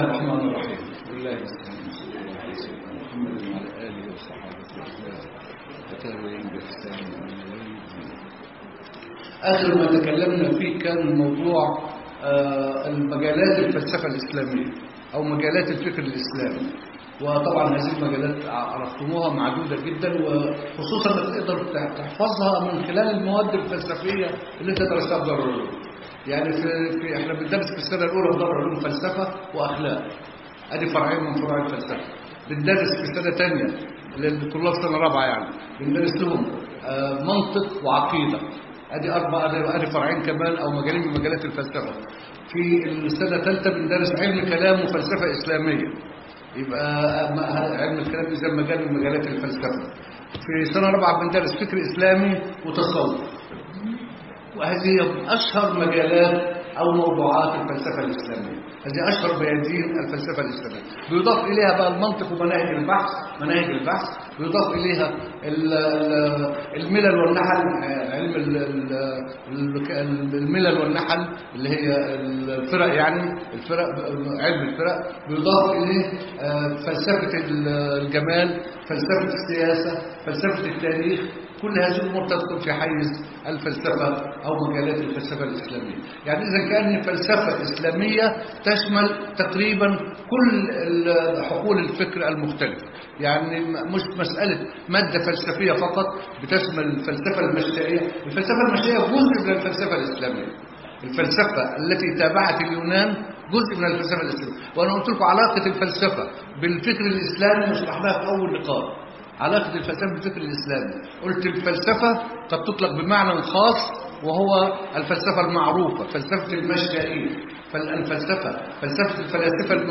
المحاضرة الخامسة لمادة موسيقى الشعر.MP3